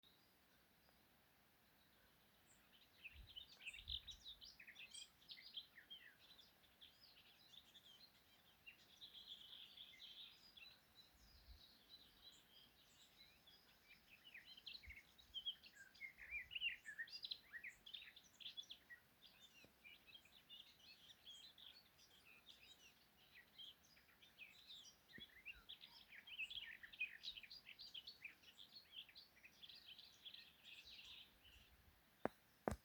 Garden Warbler, Sylvia borin
Administratīvā teritorijaDaugavpils novads
StatusSinging male in breeding season